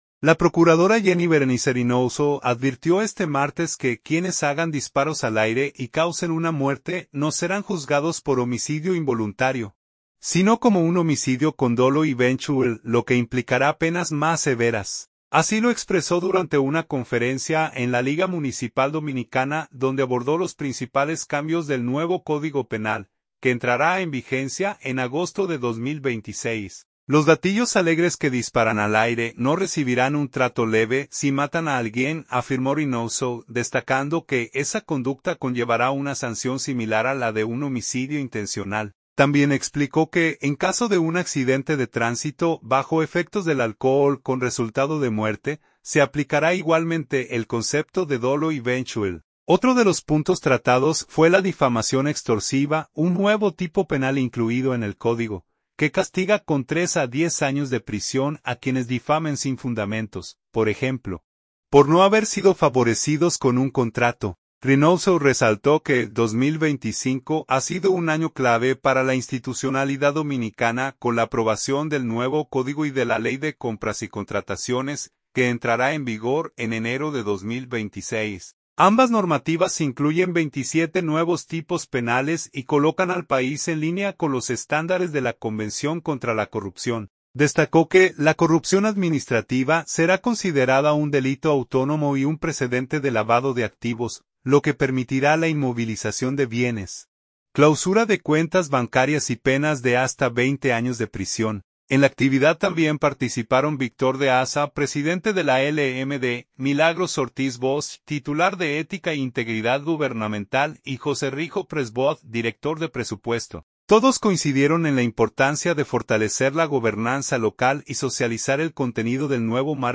Así lo expresó durante una conferencia en la Liga Municipal Dominicana, donde abordó los principales cambios del nuevo Código Penal, que entrará en vigencia en agosto de 2026.